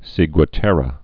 (sēgwə-tĕrə)